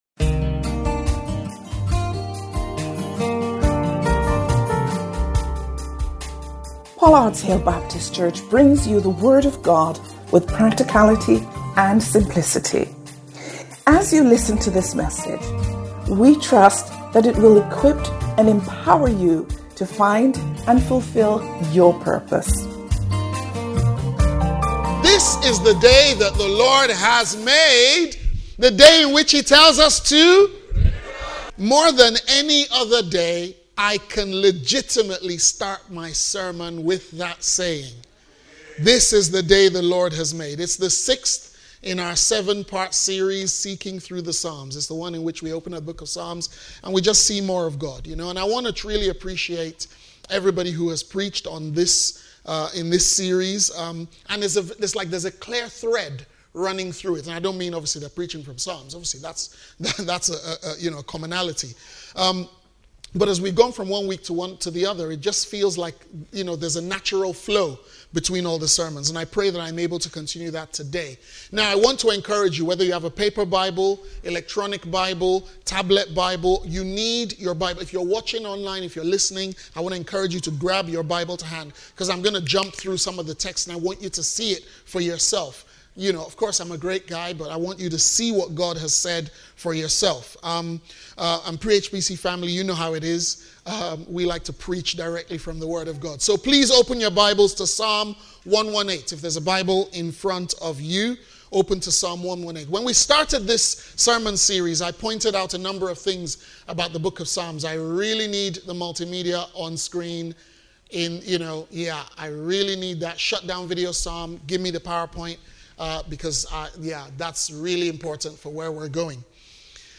Sermons – Pollards Hill Baptist Church